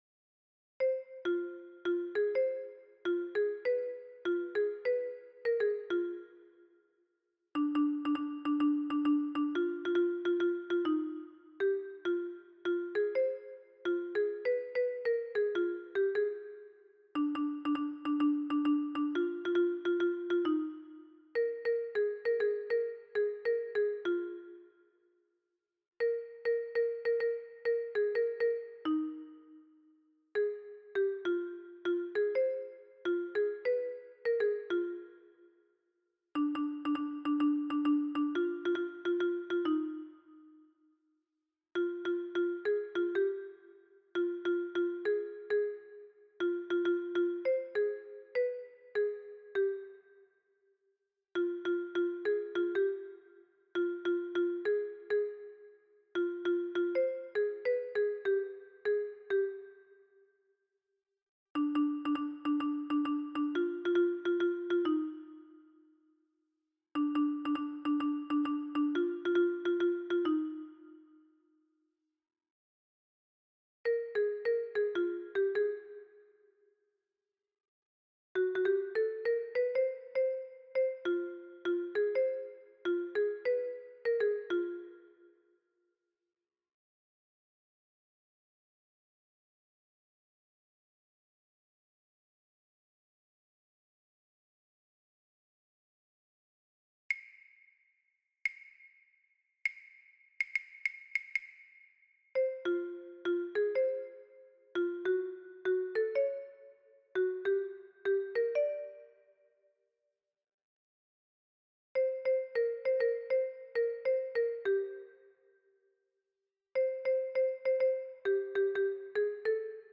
Alto et Basse